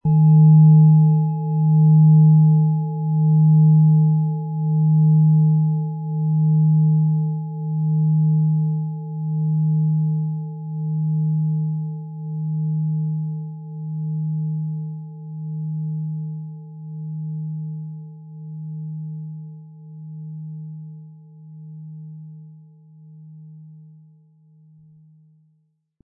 Planetenschale® Lebe schöpferische Energie & Körper und Seele verbinden mit Venus & Wasserstoffgamma, Ø 20,8 cm, 1100-1200 Gramm inkl. Klöppel
• Tiefster Ton: Wasserstoffgamma
• Höchster Ton: Mond
Um den Originalton der Schale anzuhören, gehen Sie bitte zu unserer Klangaufnahme unter dem Produktbild.
PlanetentöneVenus & Wasserstoffgamma & Mond (Höchster Ton)
MaterialBronze